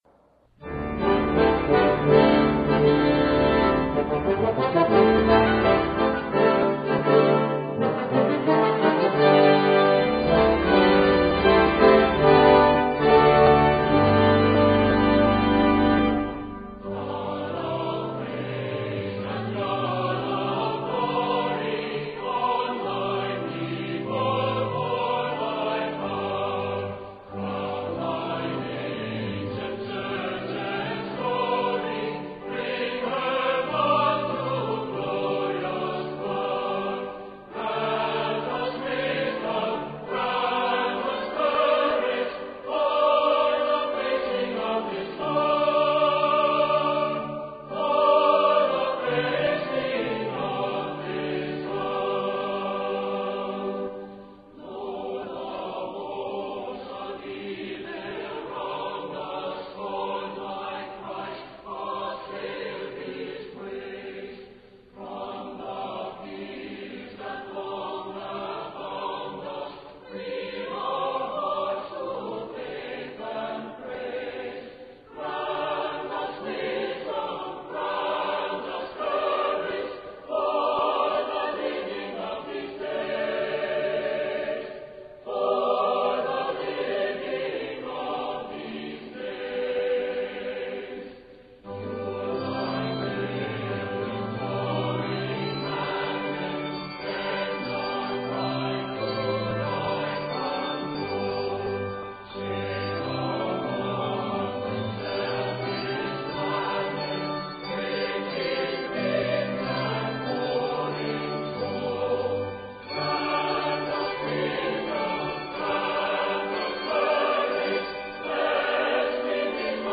Rich and traditional, perfect for congregational singing
Welsh hymn tune Arranger